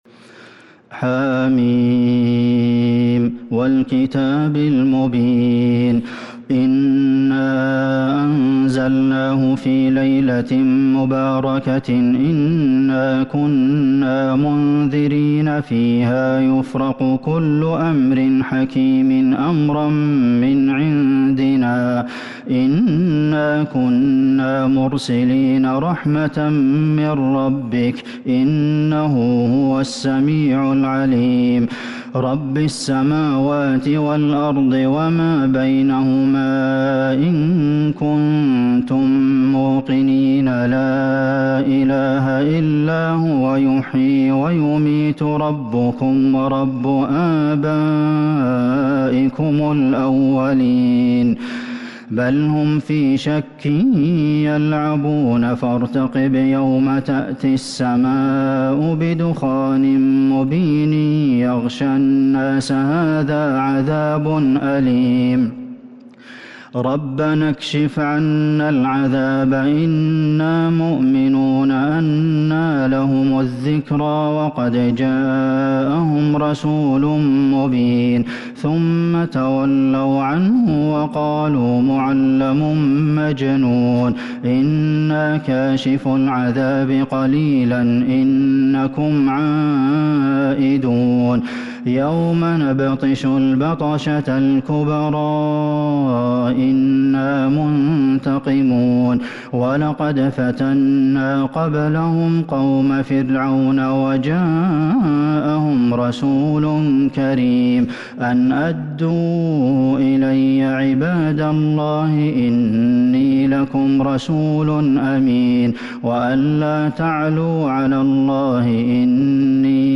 سورة الدخان Surat Ad-Dukhan من تراويح المسجد النبوي 1442هـ > مصحف تراويح الحرم النبوي عام 1442هـ > المصحف - تلاوات الحرمين